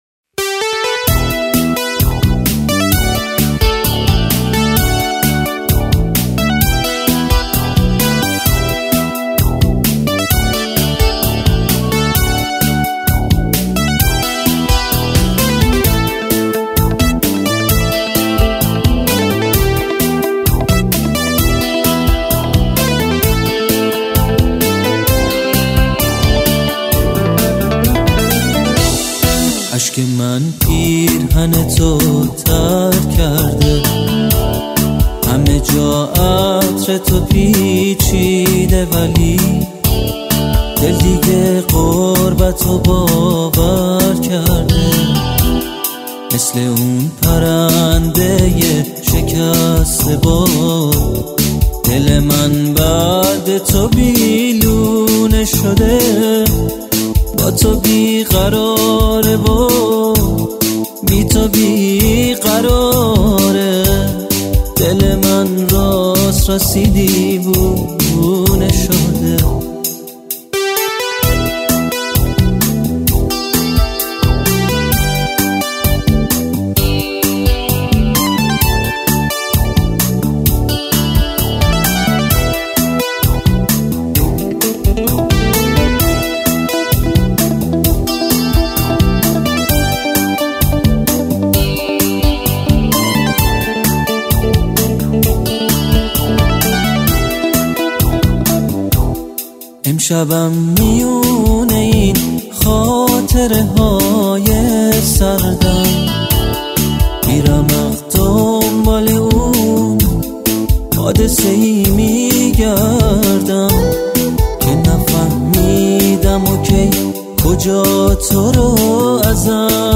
ریتم 2/4 راک یا معمولی       تمپو 130